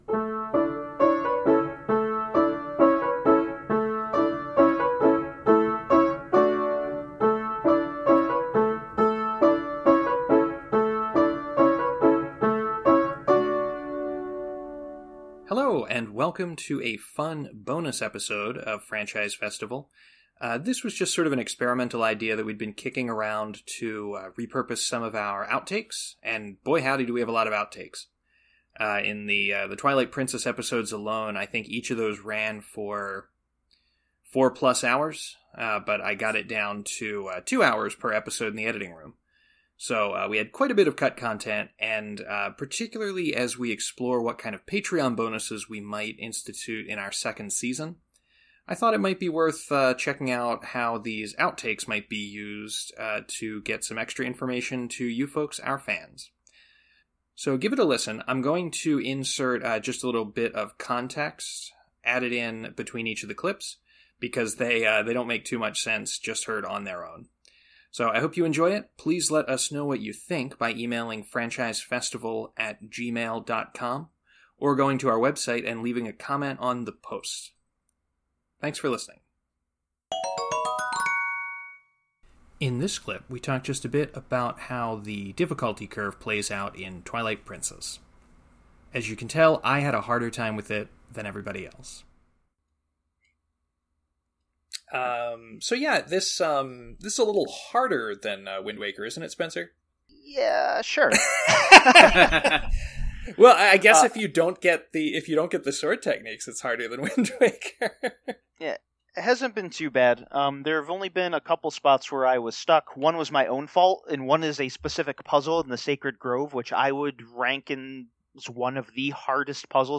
Note that audio quality and editing may be a touch rougher than standard episodes.